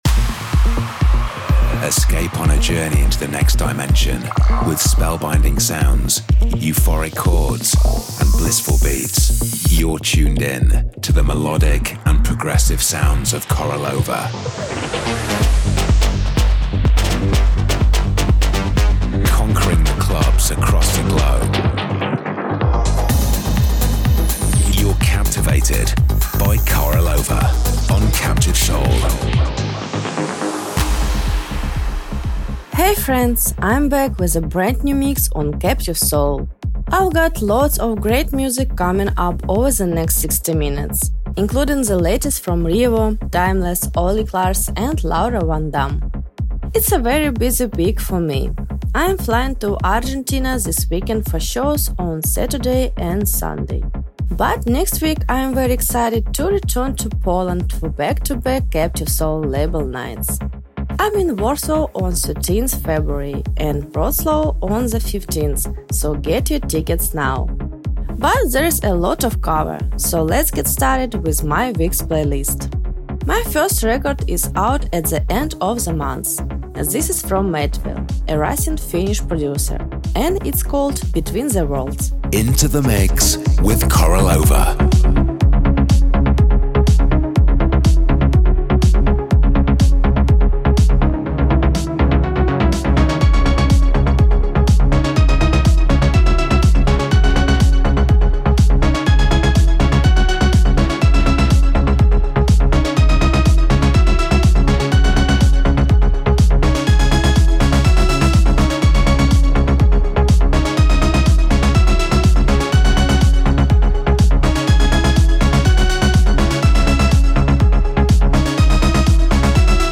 music DJ Mix in MP3 format
Genre: Progressive house